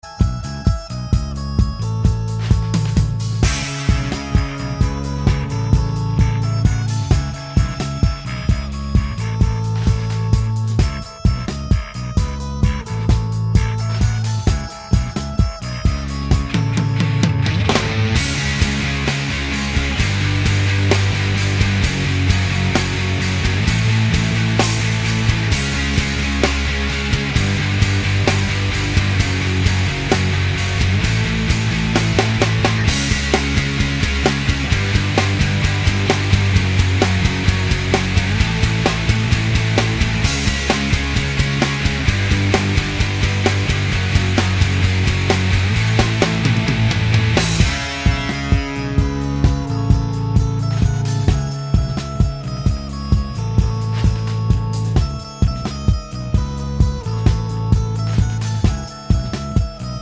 no Backing Vocals Finnish 3:57 Buy £1.50